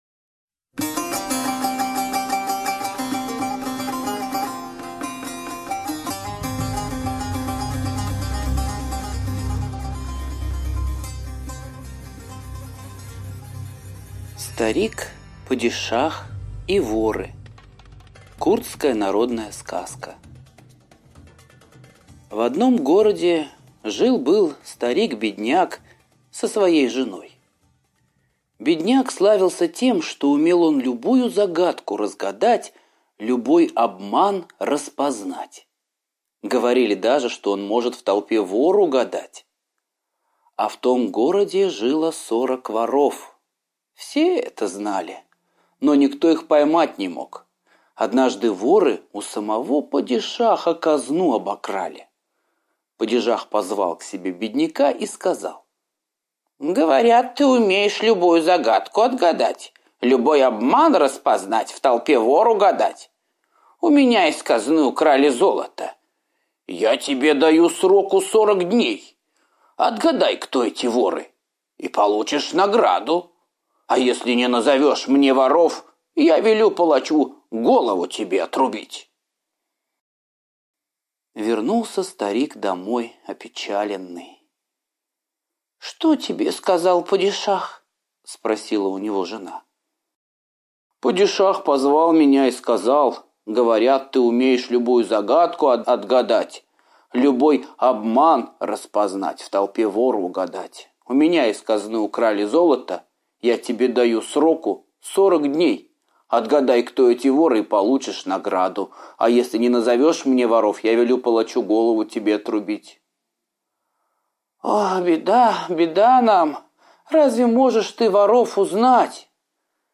Старик, падишах и воры - восточная аудиосказка - слушать онлайн